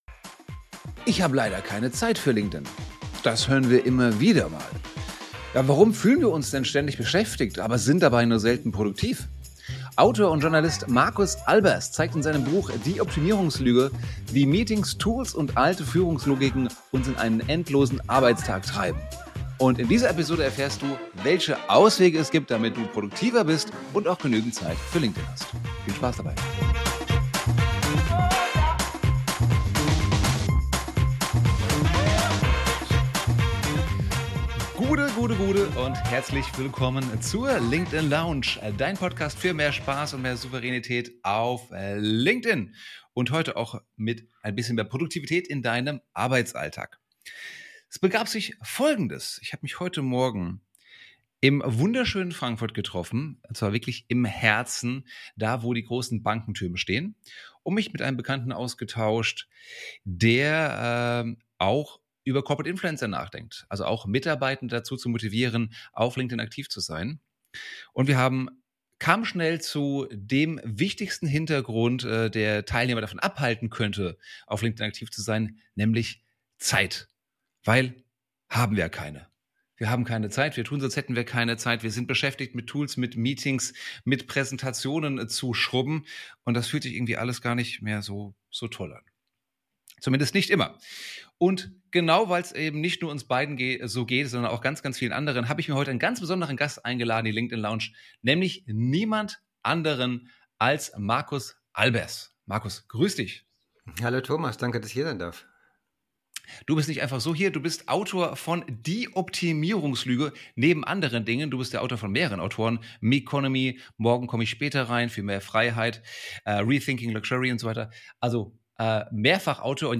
#189 Warum wir busy, aber nicht produktiv sind | Interview